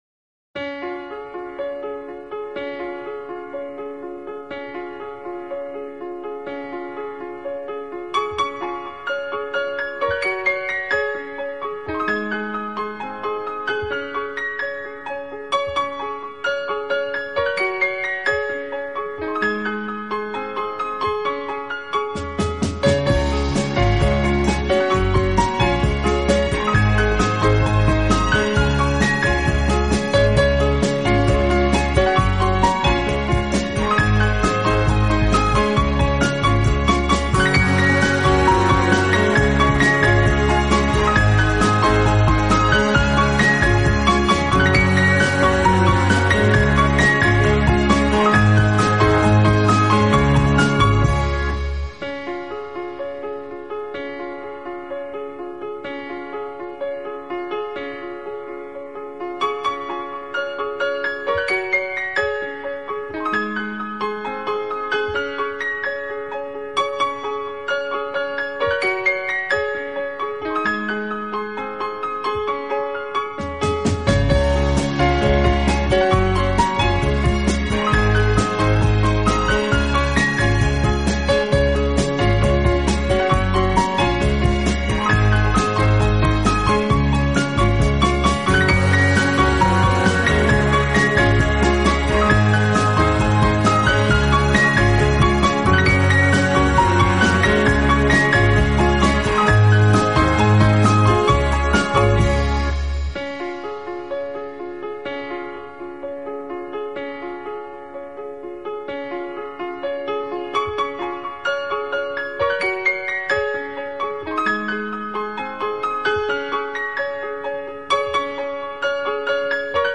音乐类型：世界音乐